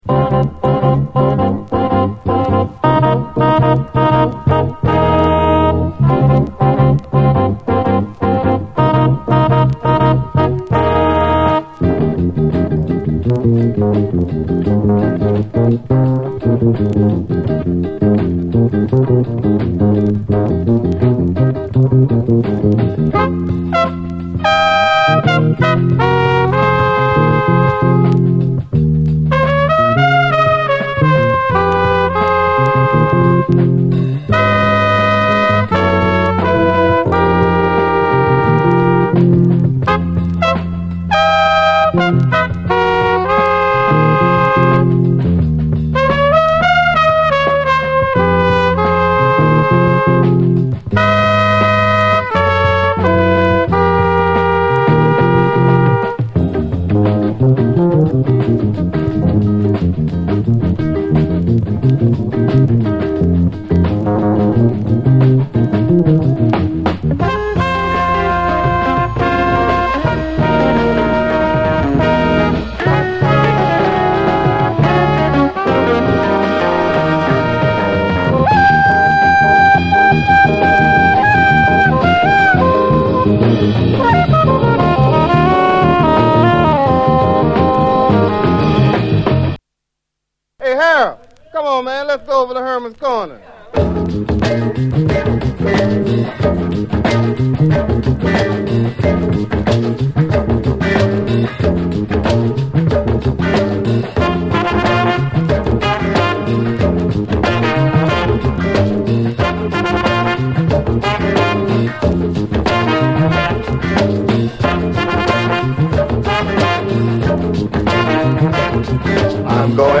ウーハー〜ダバダバ・コーラスを得意としており